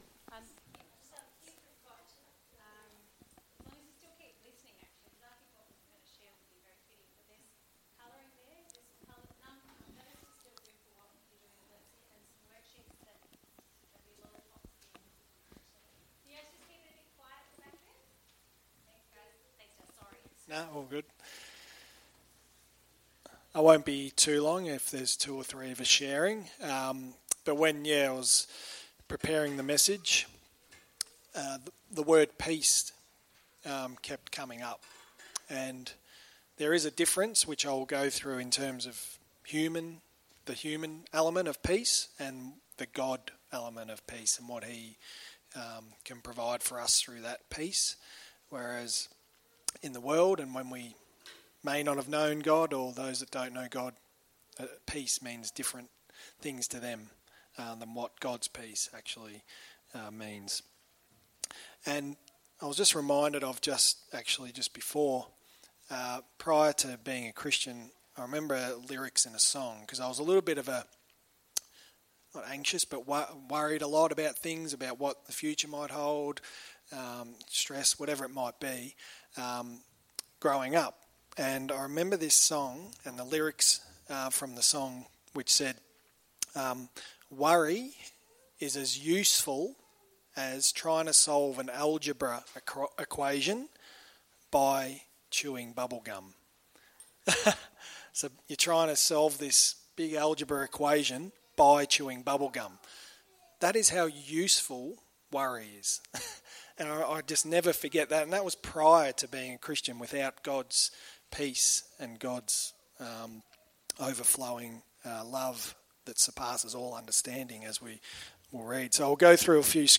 3 mini sermons